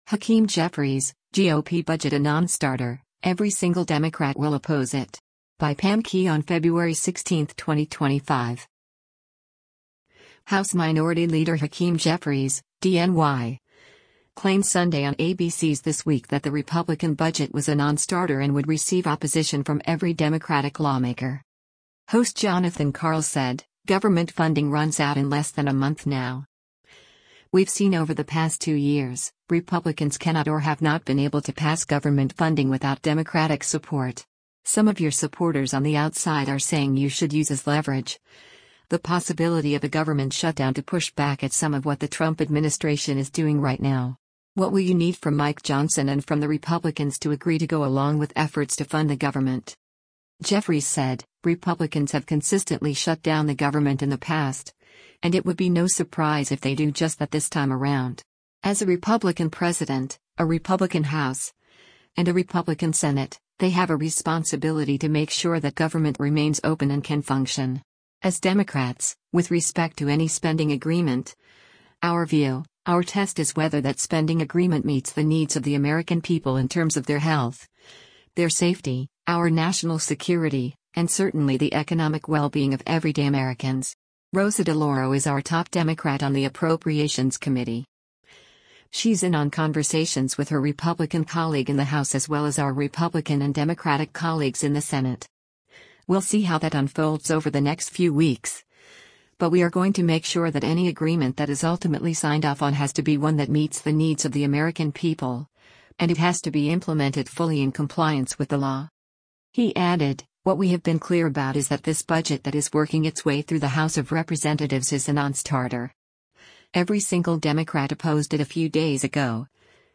House Minority Leader Hakeem Jeffries (D-NY) claimed Sunday on ABC’s “This Week” that the Republican budget was a “non-starter” and would receive opposition from every Democratic lawmaker.